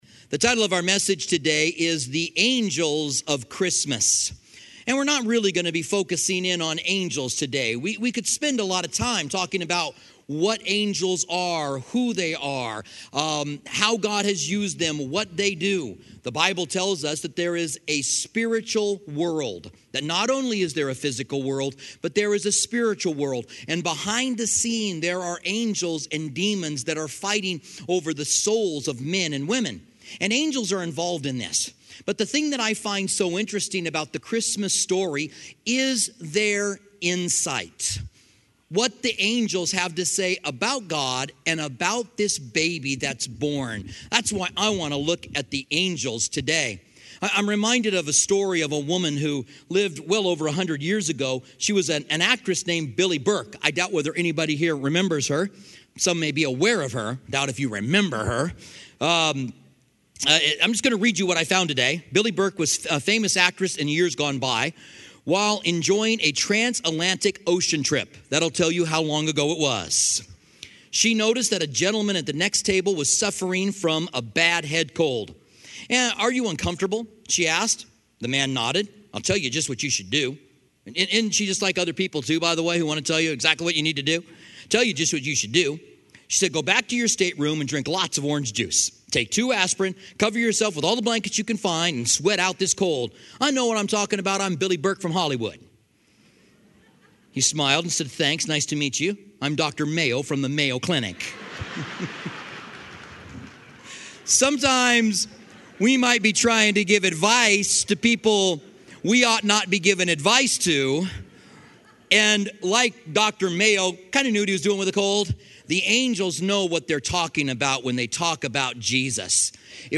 Christmas Eve Holiday Message